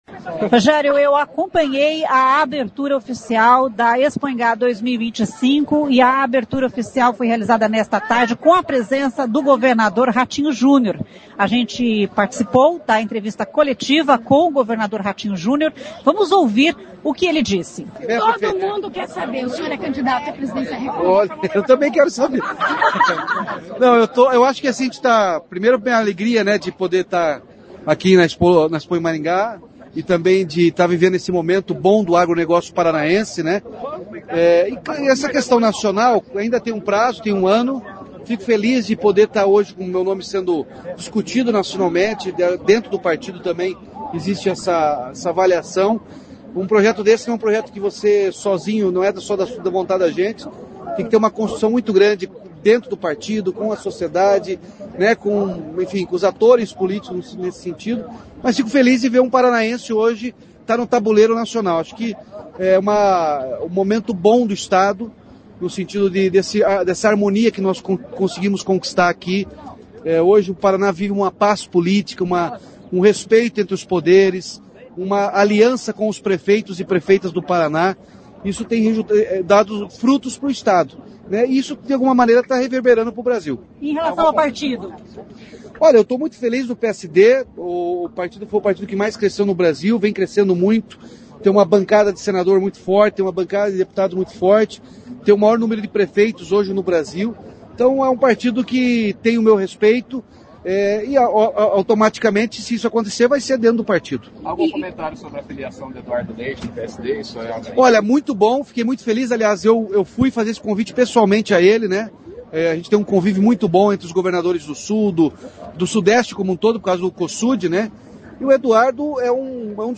O governador Ratinho Jr participou nesta sexta-feira (9) da abertura oficial da Expoingá 2025. Em entrevista coletiva o governador falou sobre um programa de financiamento para o agricultor e sobre política. O governador respondeu aos questionamentos sobre a corrida presencial, o partido PSD e a entrada na sigla do governador do RS, Eduardo Leite.